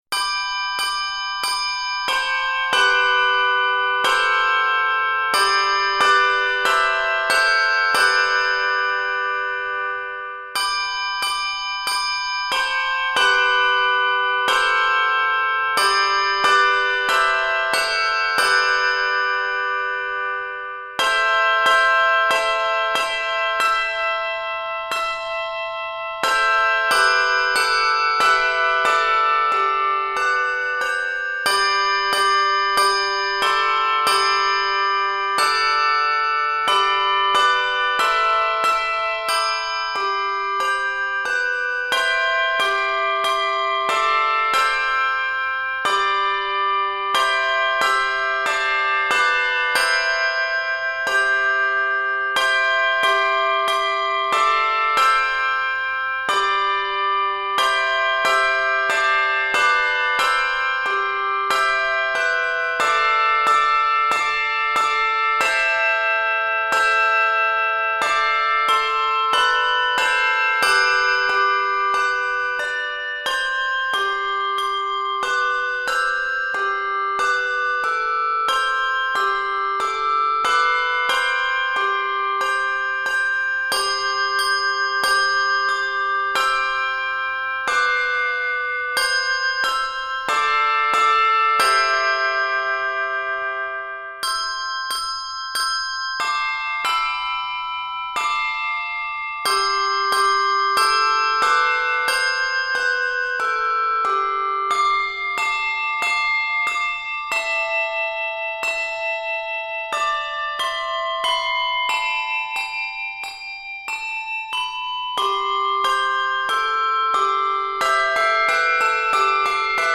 Keys of G Major and C Major.